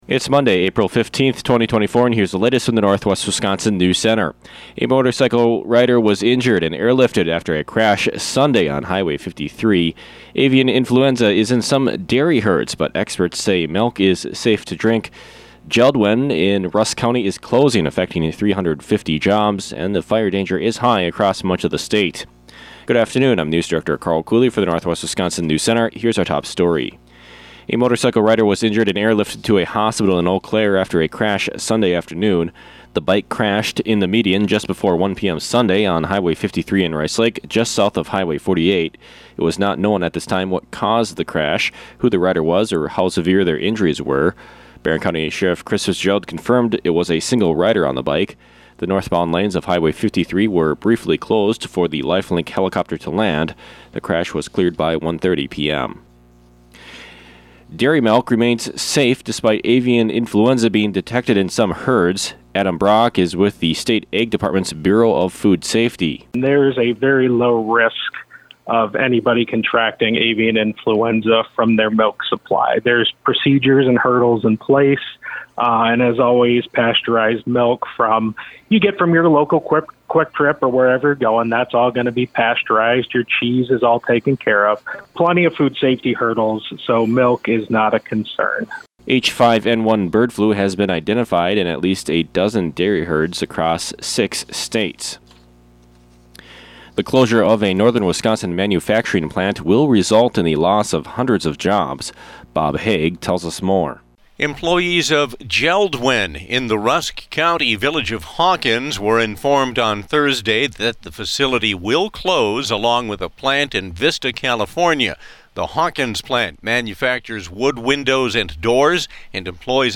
These stories and more on today’s local newscast.